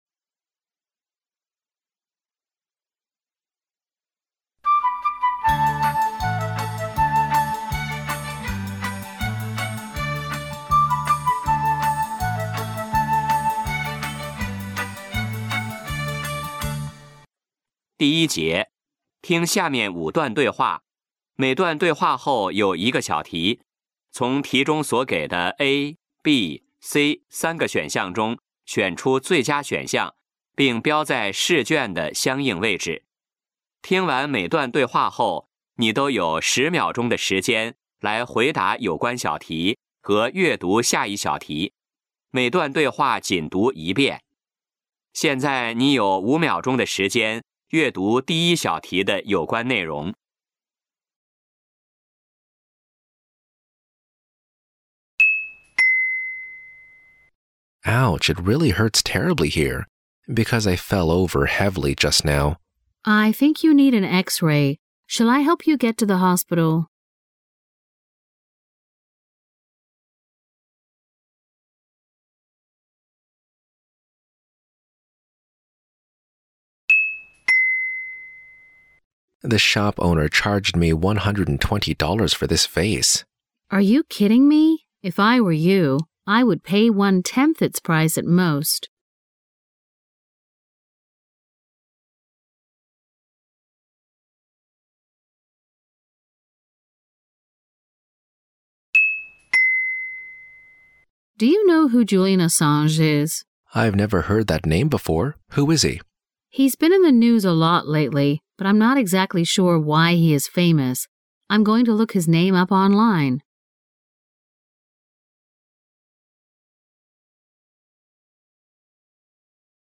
2021辽宁六校协作体高一下第三次联考英语试题及参考答案 辽宁省六校协作体高一下学期第三次联考-英语听力.mp3 点击下载MP3 [NextPage] 参考答案 2021辽宁六校协作体高一下第三次联考各科试题及参考答案汇总 (责任编辑：admin)